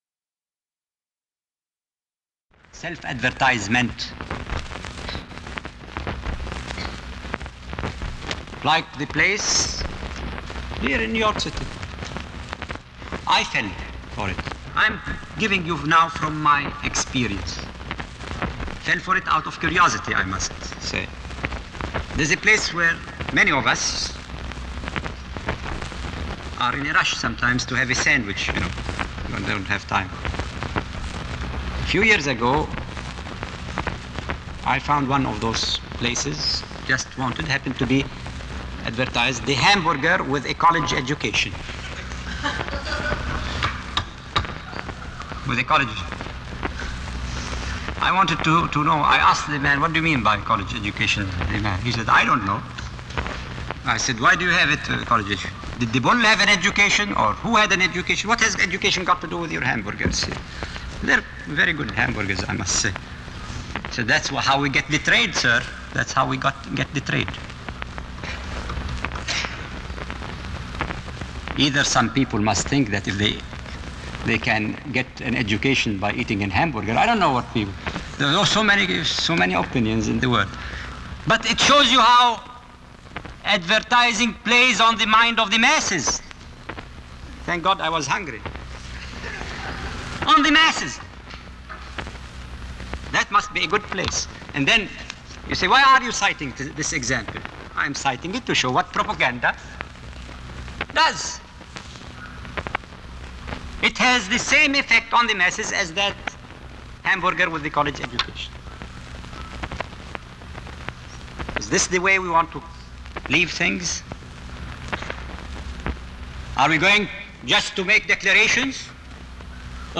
Unidentified United Nations delegate tells a story about advertising slogans